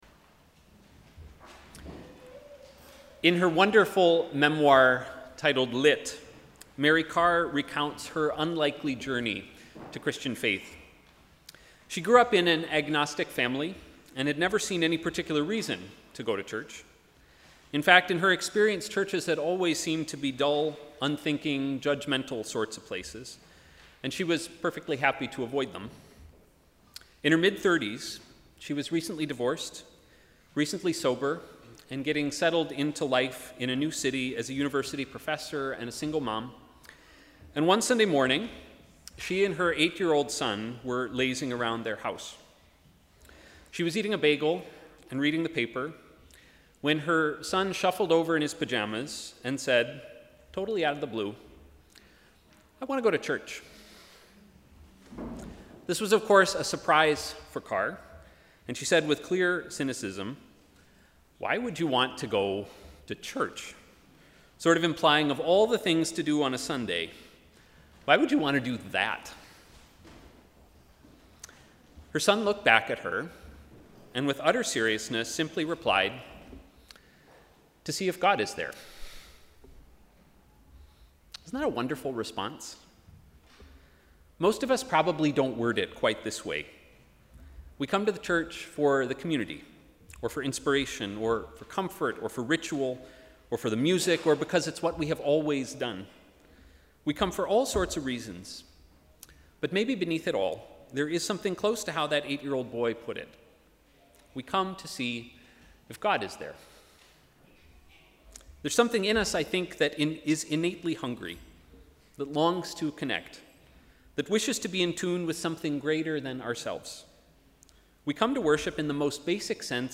Sermon: ‘Eyes to see’